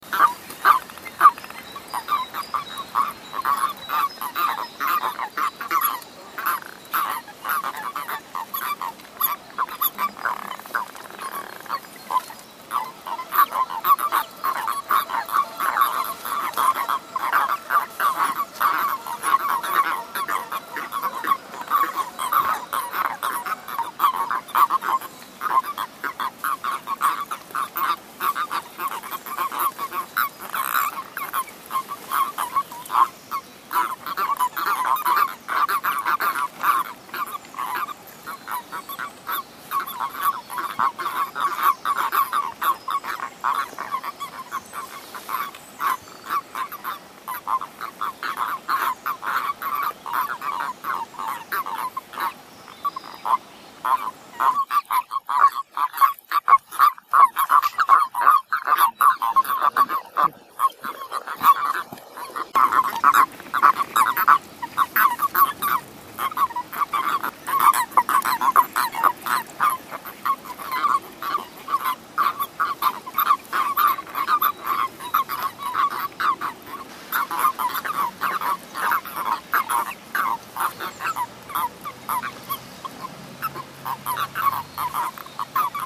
Erdkröte
English: Common toad
Latein: Bufo Bufo
Geräuschbeispiel